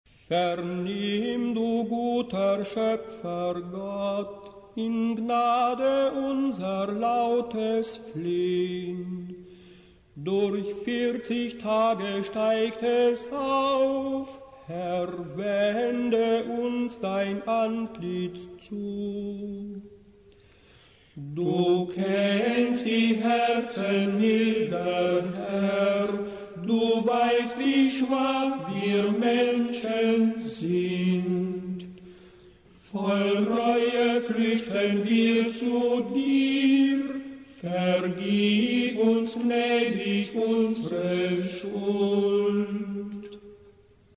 Hymnen zur Vesper in der Fastenzeit
VernimmduguterSchoepfergottHYMNUS.mp3